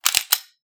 S.P.L.U.R.T-Station-13/gun_slide_lock_5.ogg at 159dd3988cdc4785f1484b036d834d9464d05566
gun_slide_lock_5.ogg